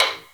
taiko-soft-hitclap.wav